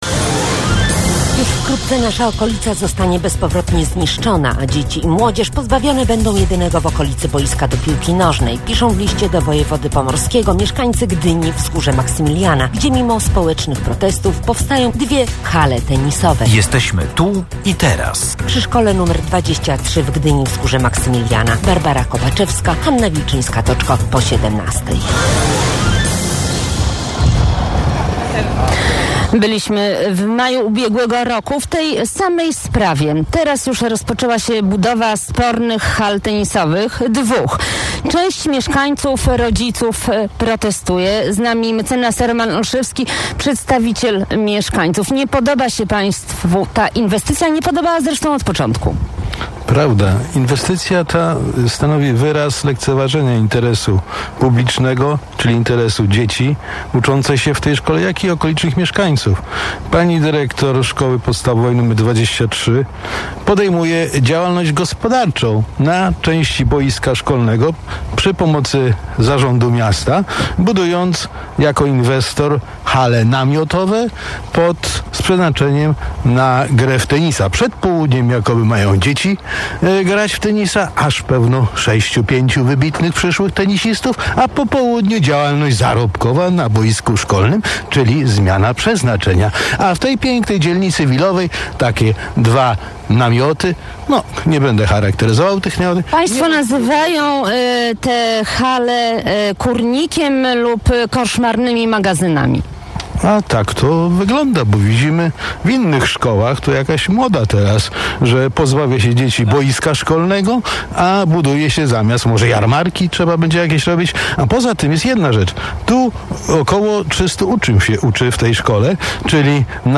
Posłuchaj audycji, którą nadawaliśmy na żywo /audio/dok1/titwzgorze.mp3